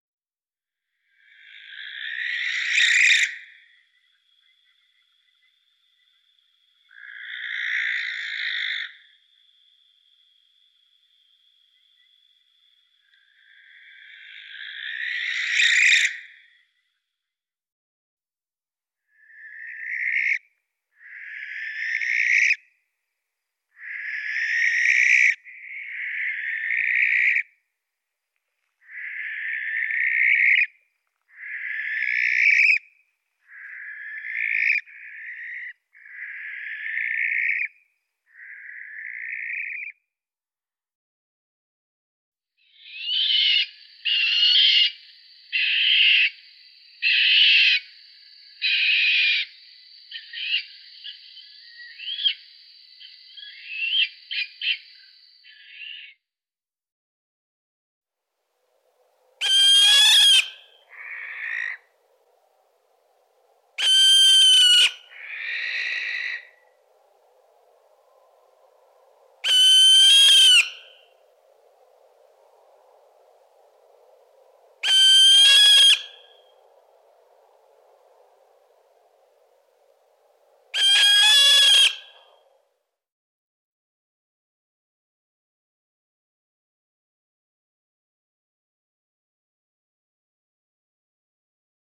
Kerkuil
De kerkuil krijst en blaast, het klinkt een beetje griezelig.
kerkuilzang.mp3